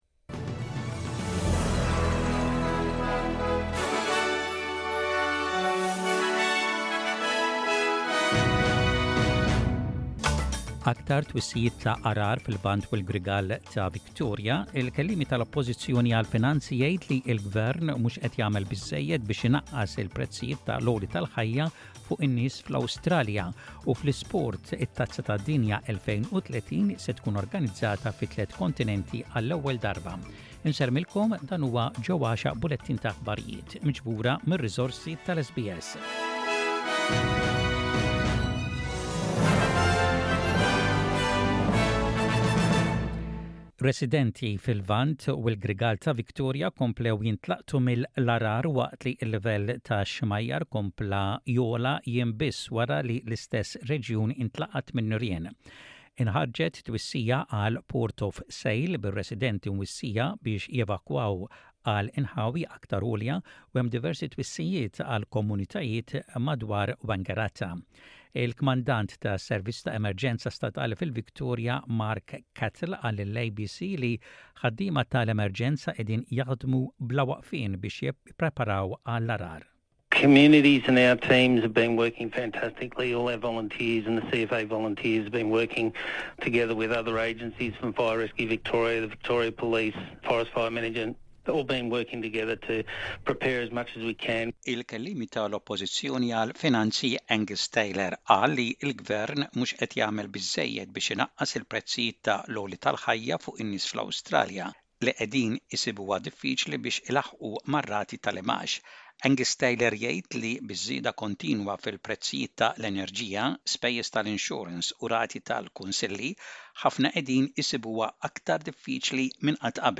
SBS Radio | Maltese News: 06.10.23